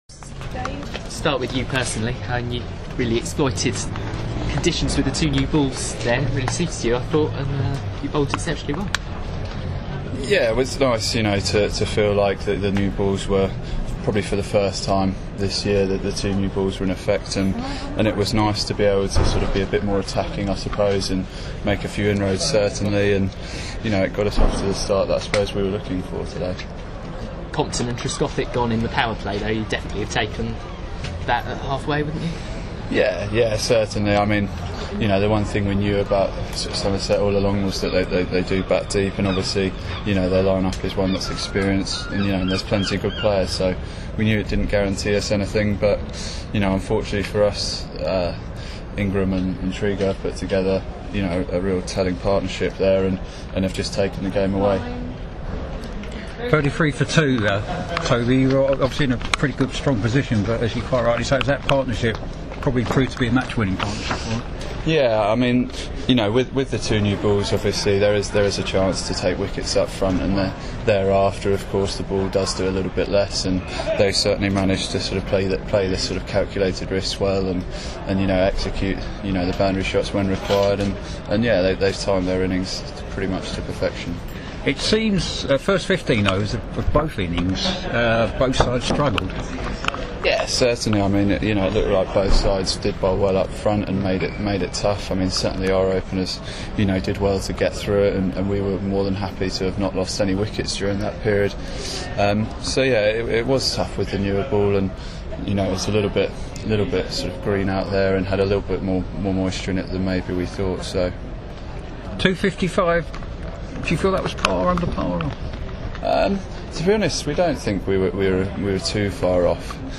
Toby Roland-Jones, speaking after Middlesex's loss to Somerset in the RLC at Lords